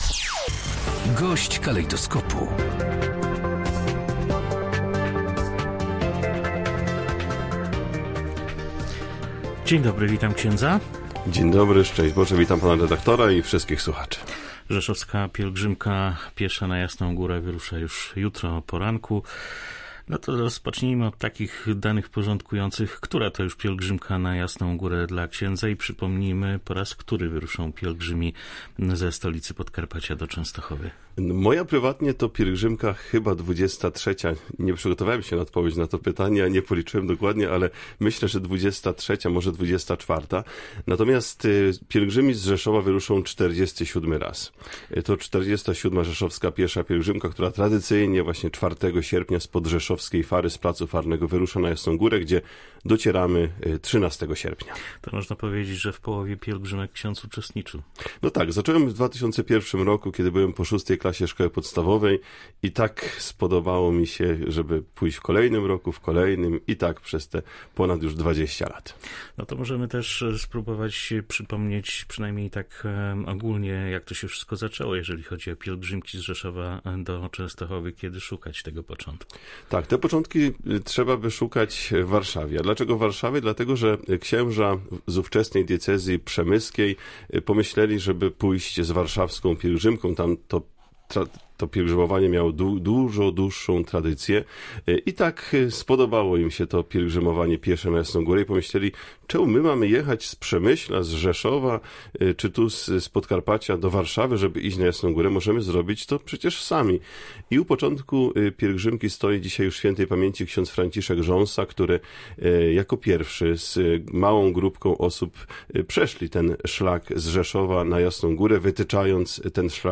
GOŚĆ DNIA. Rzeszowska Pielgrzymka Piesza